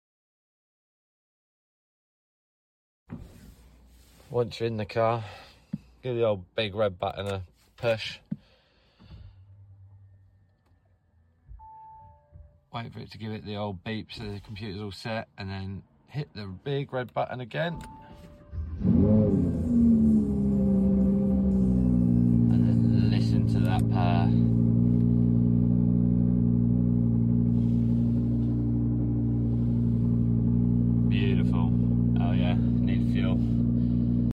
Just starting up my Audi sound effects free download
Just starting up my Audi R8 V10 and unleashing its powerful engine. The roar of this supercar is like music to my ears 🚗💨.